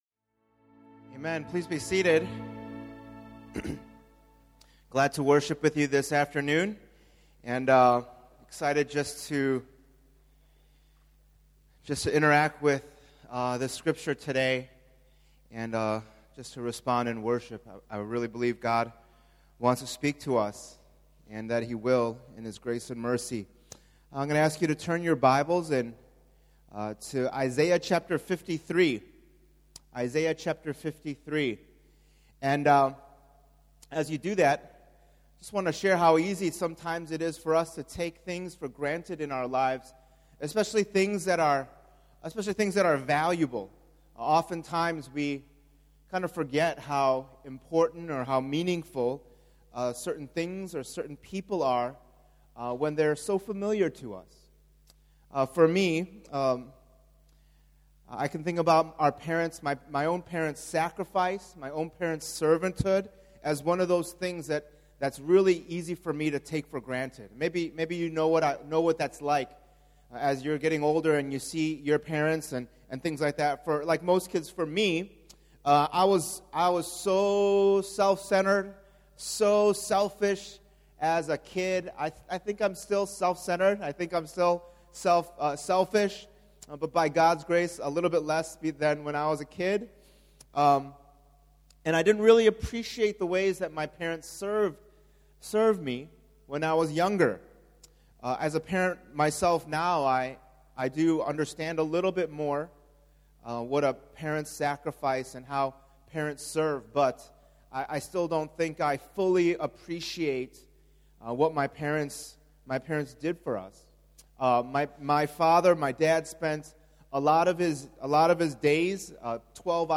We invite you to our Advent sermon series as we study how Jesus is truly God “Incarnate,” or God in human form.